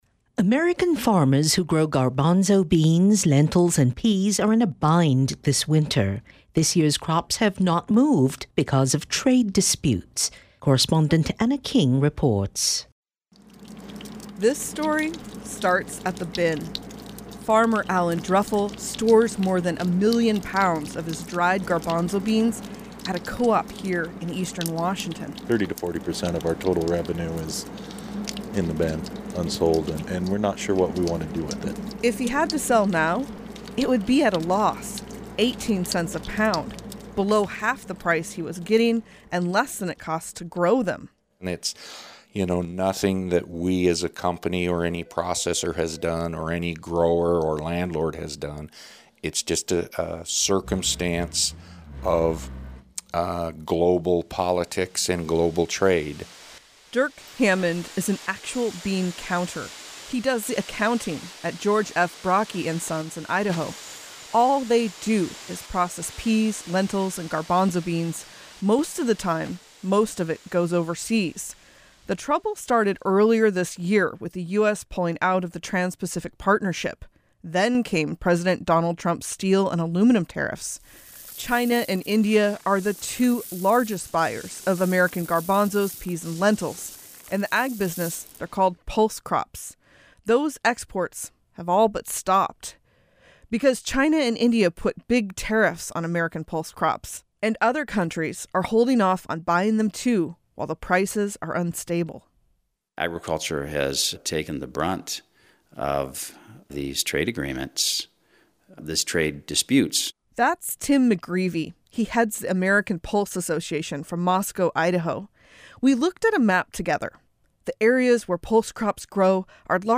Icy, fat raindrops zing off the metal roof and bounce down the corrugated sides.
But mid-afternoon, there’s just the rain.
In the deafening facility, robots whizz and whirl stacking hefty bags of garbanzo beans on wooden pallets.